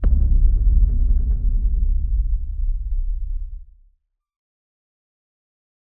Low End 10.wav